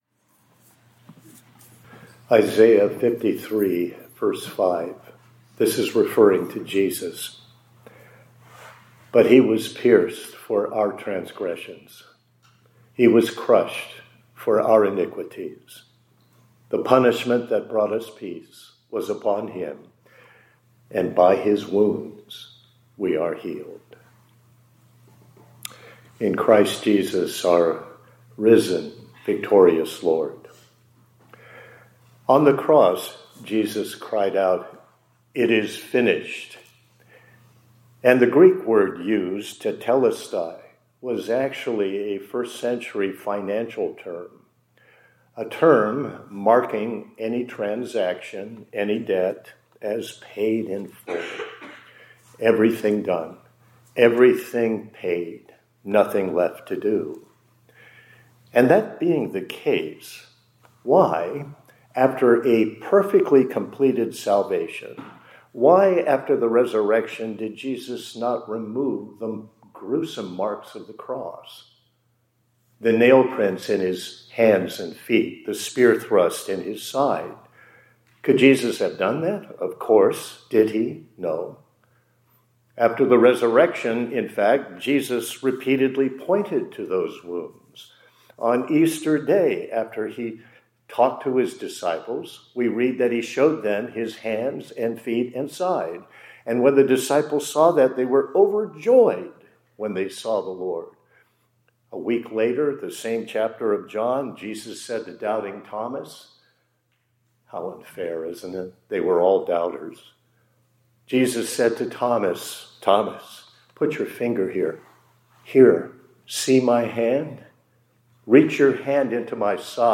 2026-04-08 ILC Chapel — His Wounds Tell a Story